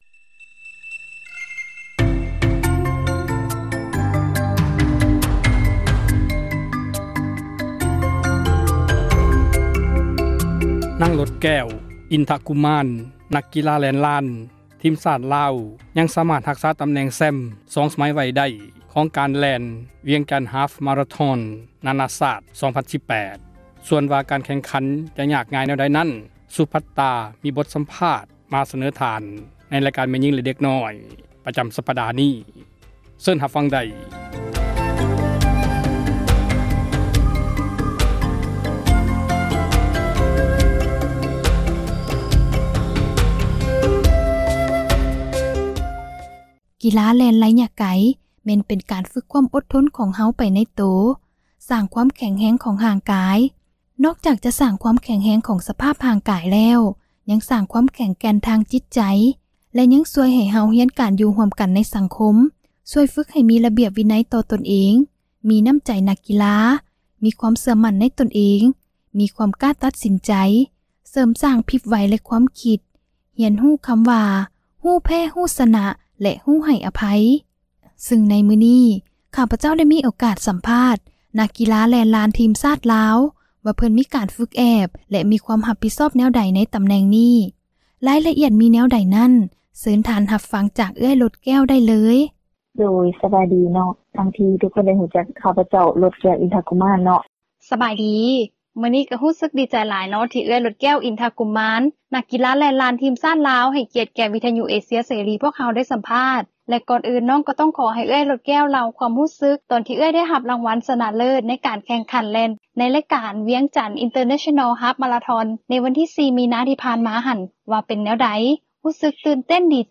ມີບົດສັມພາດ ມາສເນີທ່ານ